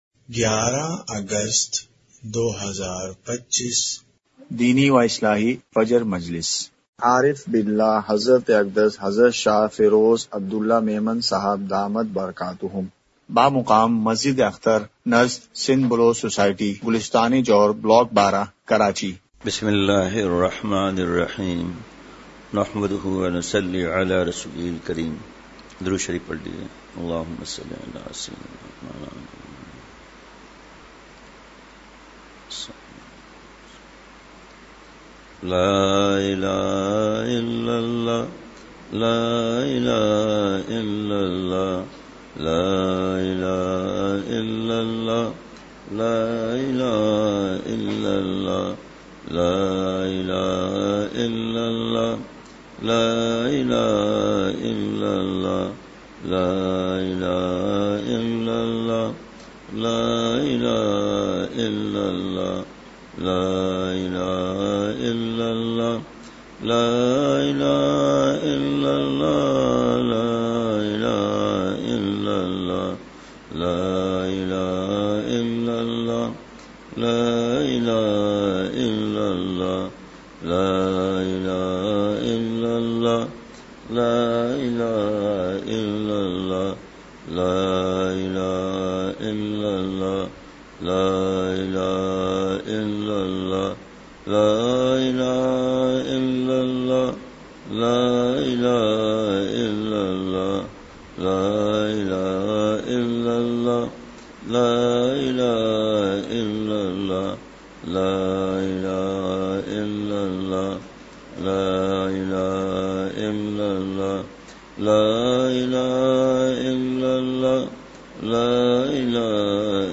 مقام:مسجد اختر نزد سندھ بلوچ سوسائٹی گلستانِ جوہر کراچی
مجلسِ ذکر:کلمہ شریف کی ایک تسبیح۔۔۔!!